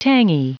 Prononciation du mot tangy en anglais (fichier audio)
Prononciation du mot : tangy